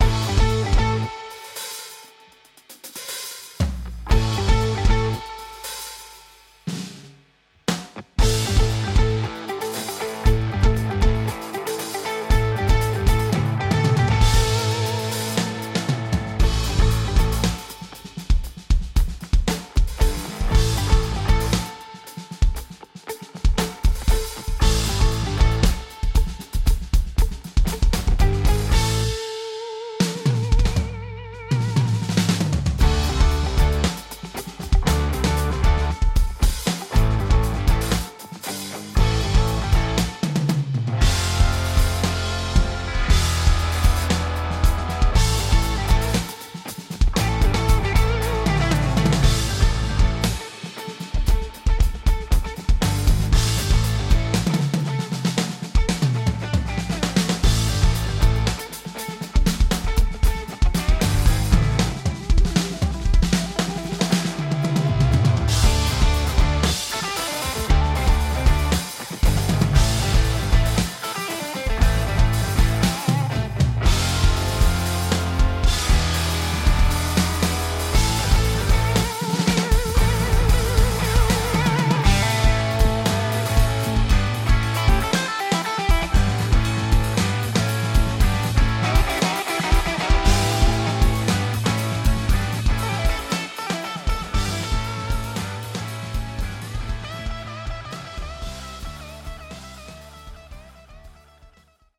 DW Soundworks 是 DW（Drum Workshop）与 Roland 联合开发的旗舰级虚拟鼓音源插件，主打真实原声鼓采样、多麦克风混音、深度自定义与可扩展音色库，是追求顶级原声鼓质感的制作人首选。